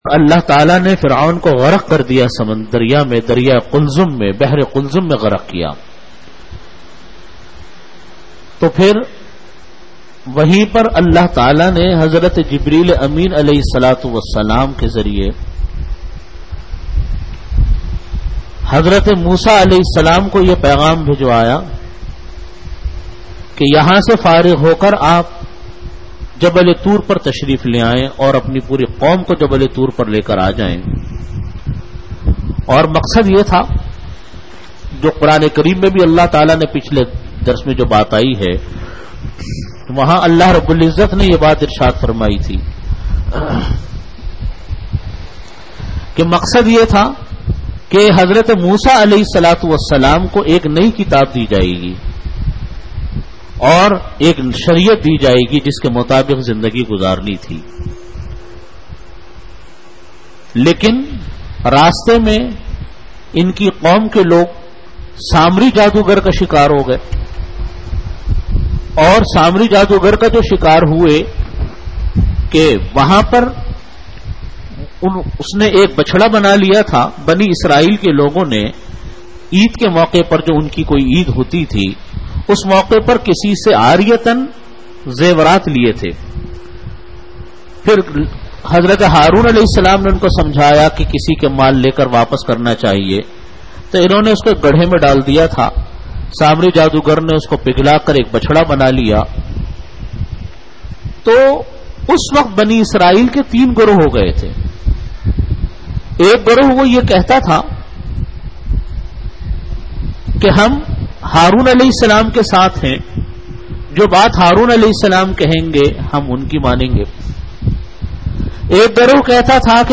Delivered at Jamia Masjid Bait-ul-Mukkaram, Karachi.